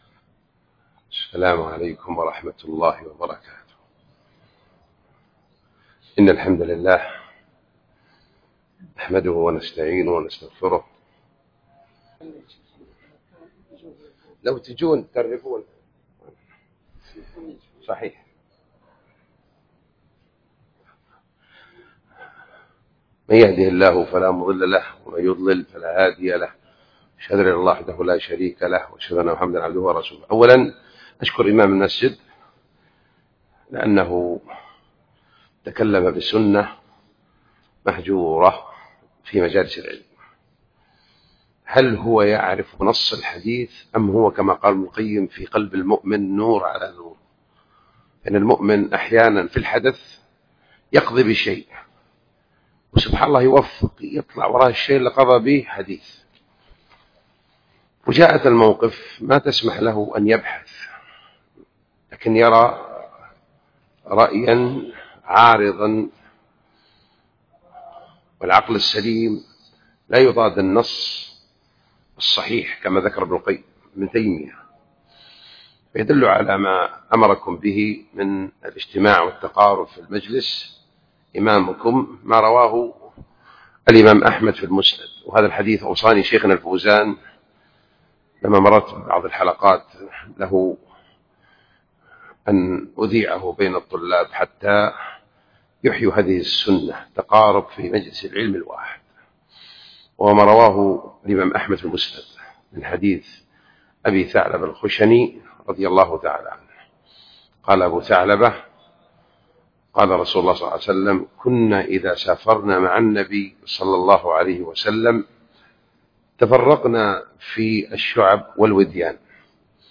المحاضرات المنهجية
661 فضل الإحسان للمرأة وصيانة حقوقها بجامع العود بحي الخمرة .